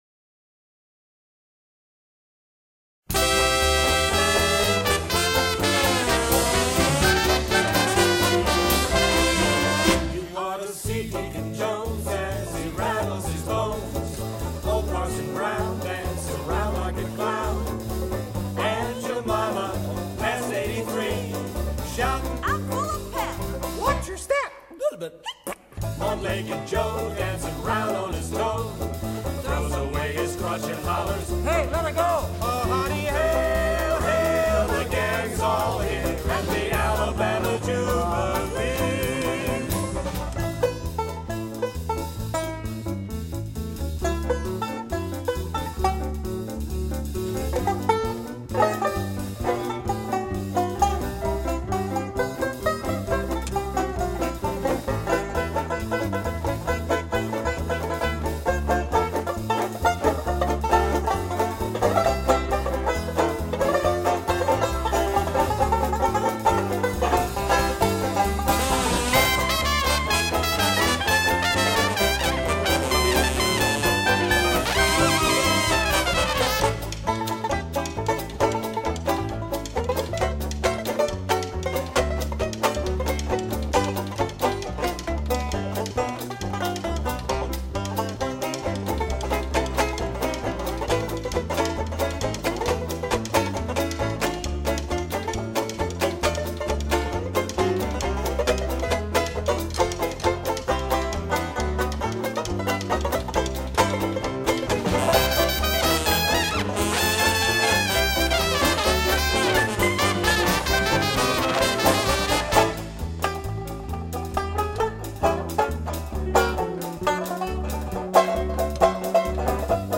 钢琴，声乐
班卓琴
小号
单簧管